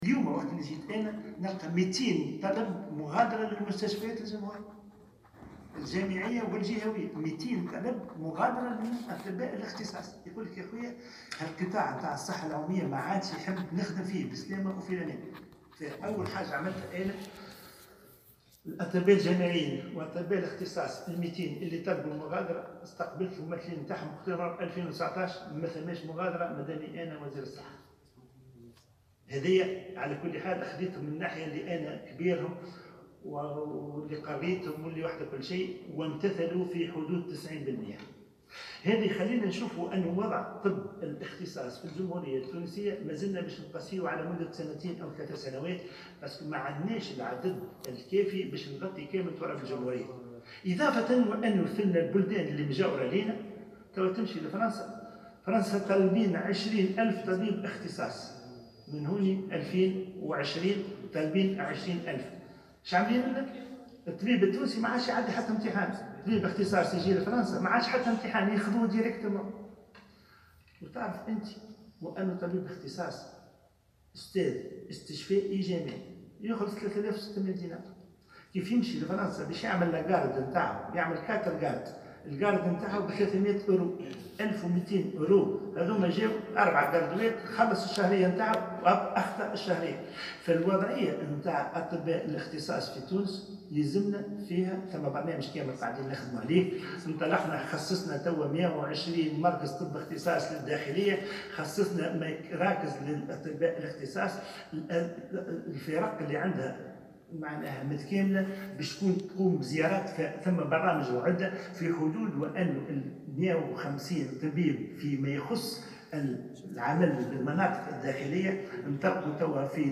أكد وزير الصحة عبد الرؤوف الشريف خلال جلسة استماع له اليوم الإثنين 7 جانفي 2019 أنه تلقى منذ تسلمه منصبه 200 طلب مغادرة لأطباء الاختصاص من المستشفيات الجامعية و الجهوية.